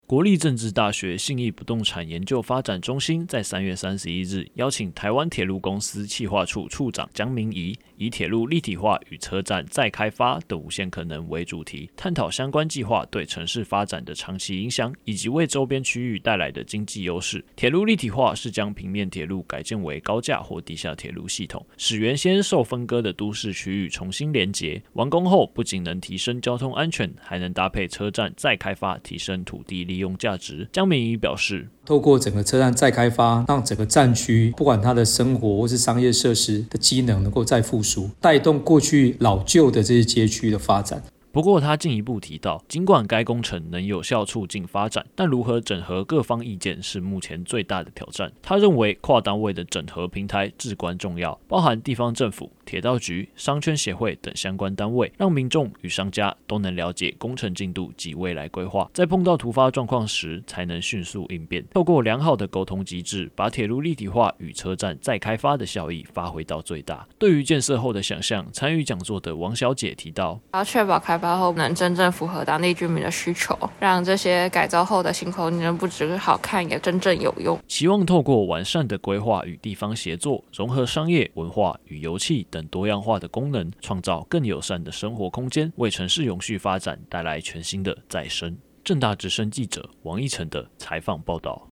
政大之聲實習廣播電台-大台北重點新聞